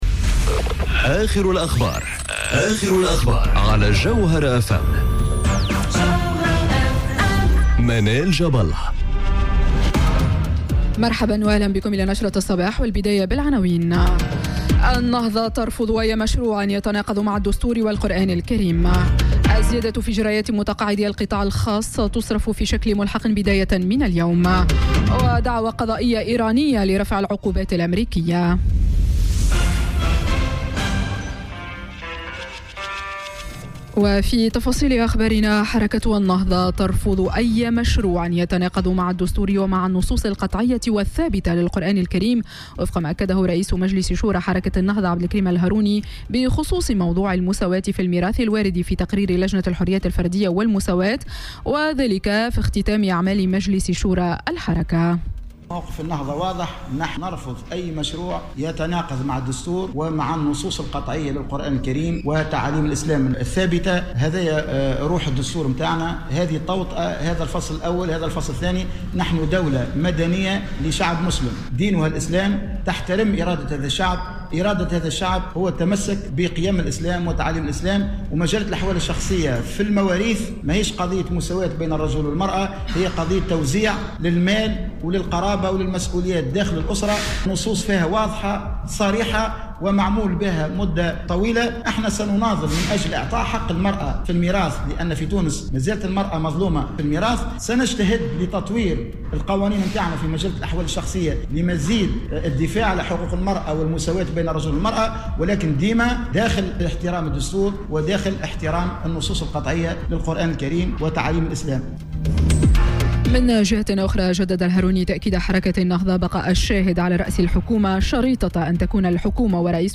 نشرة أخبار السابعة صباحا ليوم الإثنين 27 أوت 2018